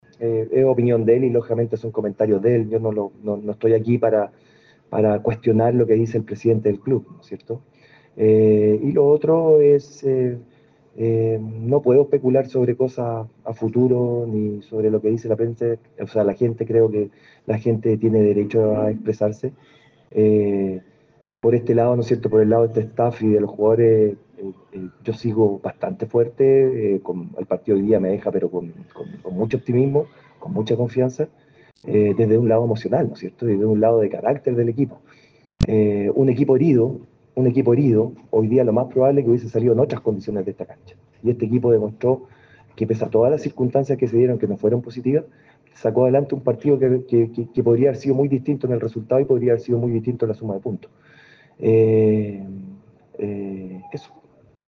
Por otro lado al ser consultado Mario Salas por lo que se vio en el estadio y las palabras de Marcelo Salas, el dijo que no se hacía cargo de ello y que entendía a la gente la manera en que se manifestaban por la campaña del equipo. Al ser consultado por su continuidad expresó que no comentaban cosas que pueden suceder a futuro.